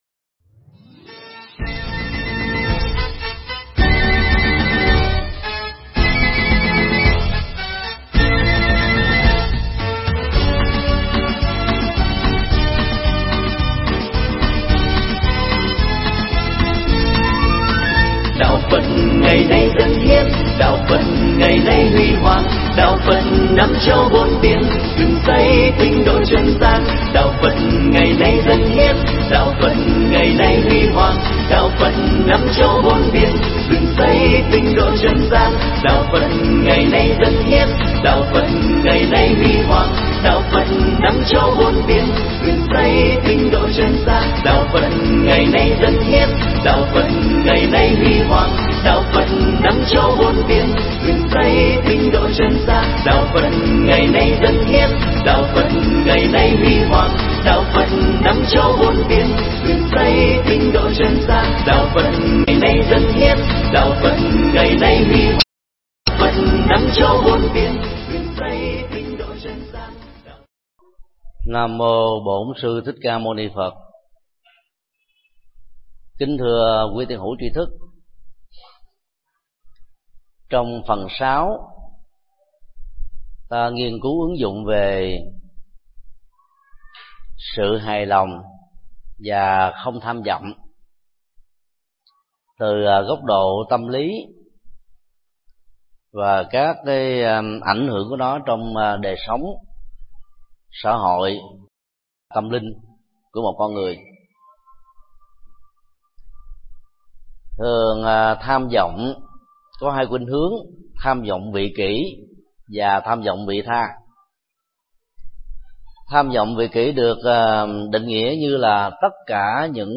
Mp3 Pháp thoại Kinh Di Giáo 06: Hài lòng và không tham vọng được thầy Thích Nhật Từ giảng tại chùa Xá Lợi ngày 22 tháng 04 năm 2012